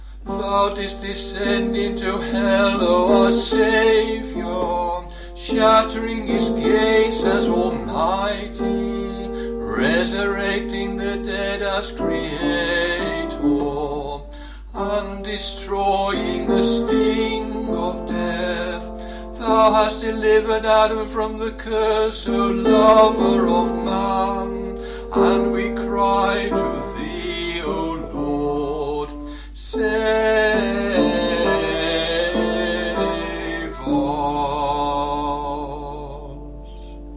TONE 5 KONTAKION
tone-5-kontakion.mp3